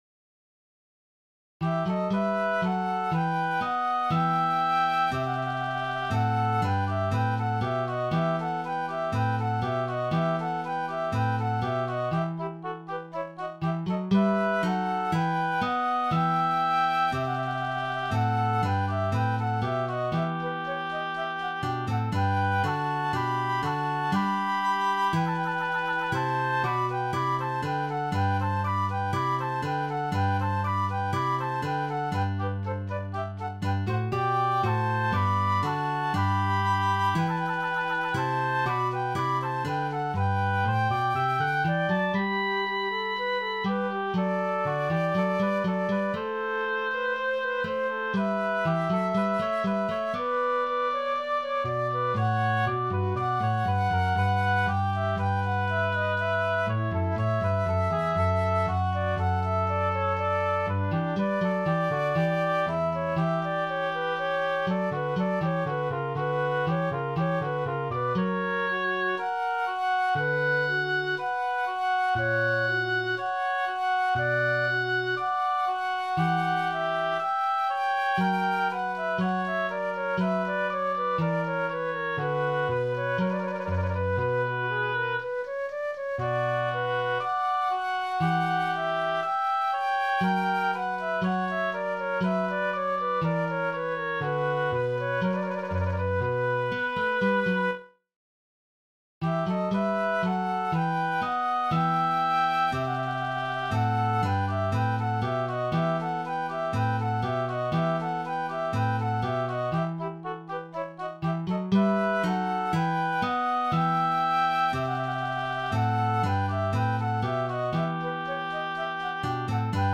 Arranged for Guitar Trio